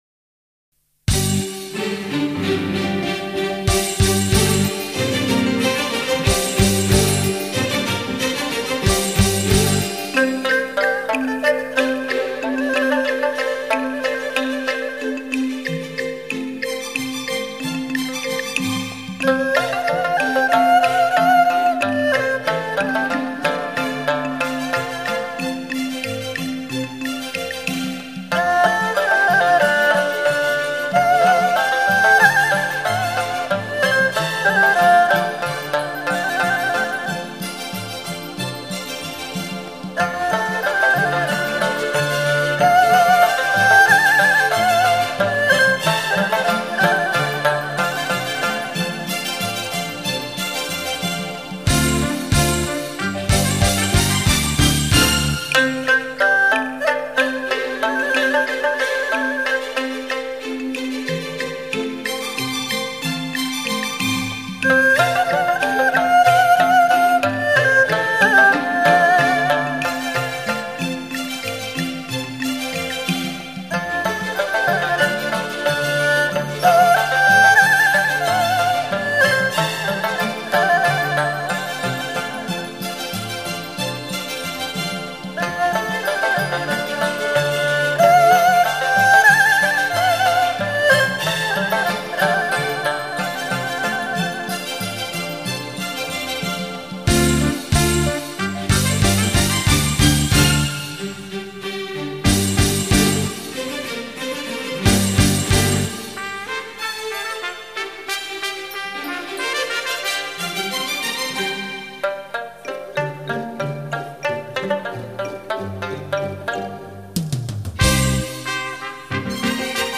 各地民歌改编音乐·轻曲妙韵系列 现抓之三
专辑类型：纯音乐
乐队由西洋管弦乐器与中国民
族乐器混合组成，编曲配器细腻， 演奏者多为中国的教授级独奏家和